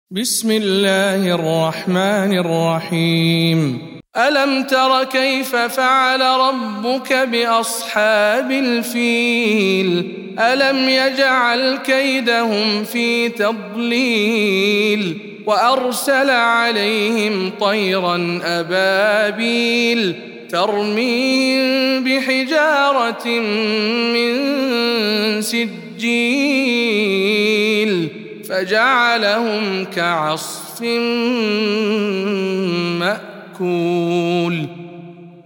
سورة الفيل - رواية إسحاق عن خلف العاشر